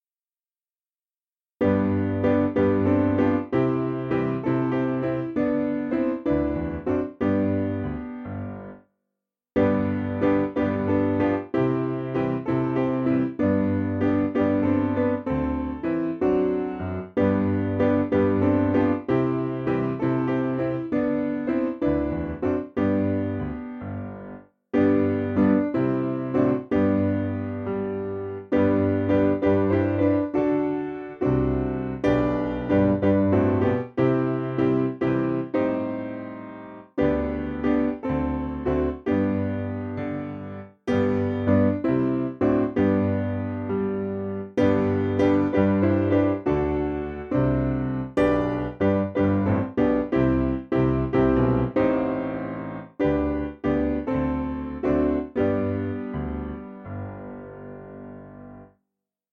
Information about the hymn tune [Yesterday, today, forever] (Burke).
Key: G Major